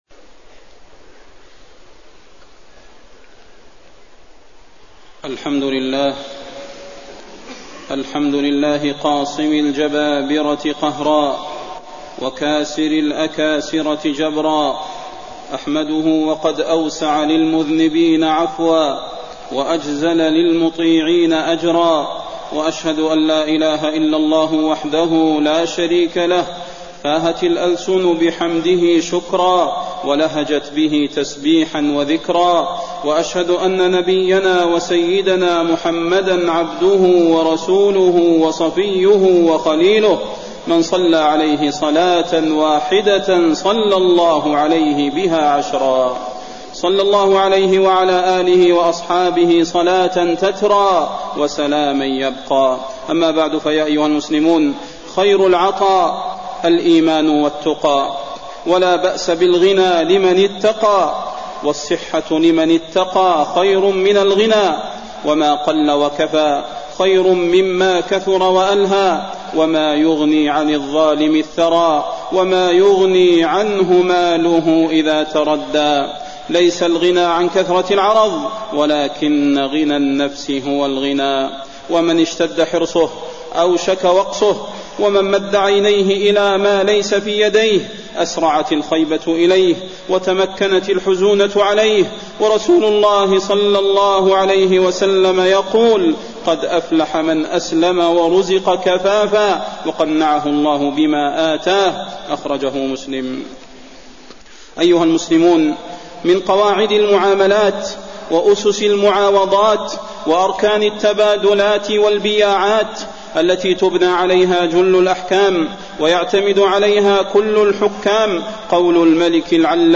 تاريخ النشر ١٧ ربيع الثاني ١٤٣١ هـ المكان: المسجد النبوي الشيخ: فضيلة الشيخ د. صلاح بن محمد البدير فضيلة الشيخ د. صلاح بن محمد البدير التعاملات المالية وحقوق العمال